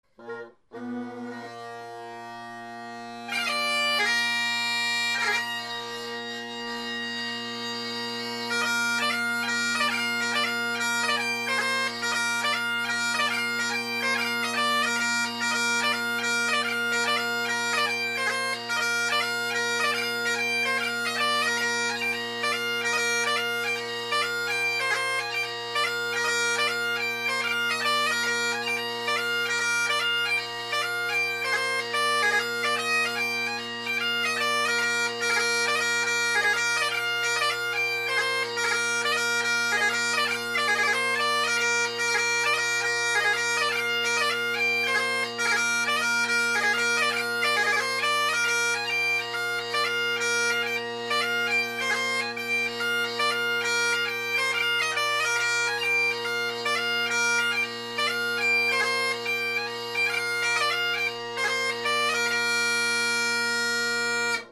This is a 4 part reel that’s pretty easy overall, with a beefy 3rd part that incorporates 2 low A taorluaths and 1 bubbly note from C to B. For Grade IV players, I’ve got a 2 part reel for you: Kate Dalrymple.
Murdo MacGillivray of Eoligarry 2016-11-06 – need to hold the C note in the “{g}c{d}A{e}A” phrase at the end of lines, need to clean up “A{d}c” movements and watch for crossing noises.